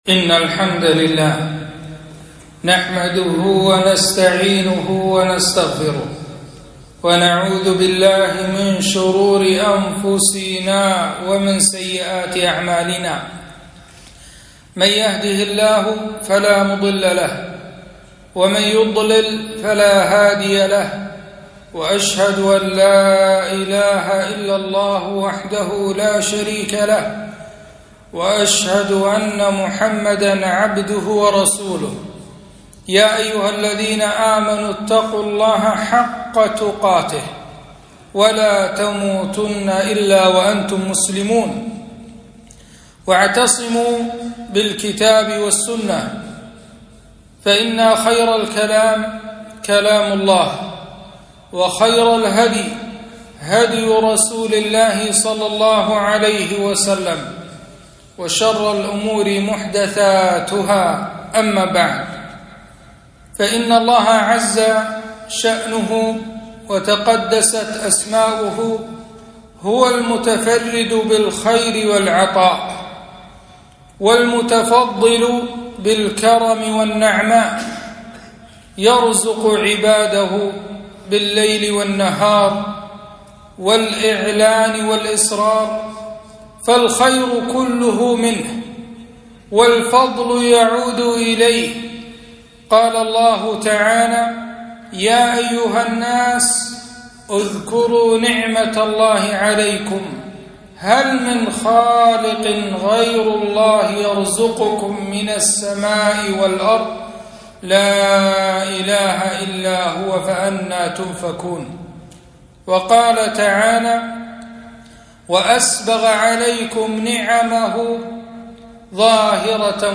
خطبة - وما بكم من نعمة فمن الله